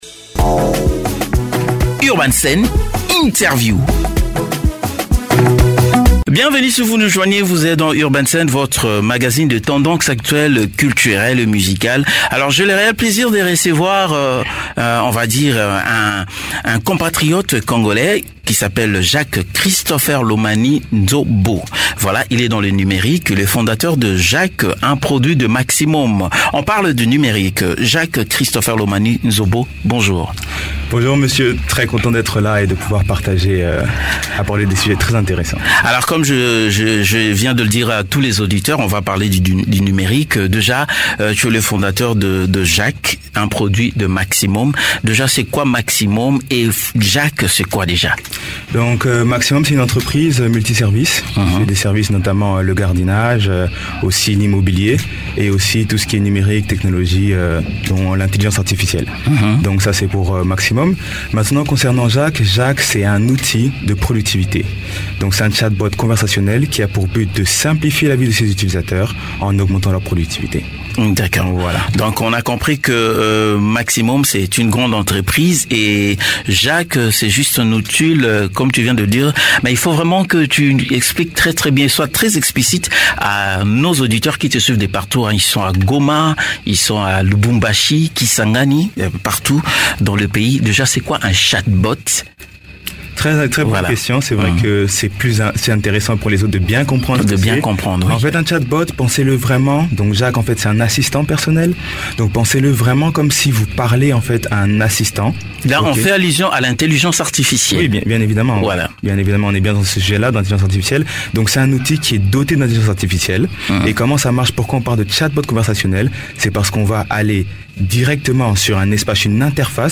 Posez des questions, donnez-lui des tâches, et il répondra en conséquence. Pour en savoir plus écoutez l'interview que monsieur